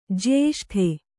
♪ jyēṣṭhe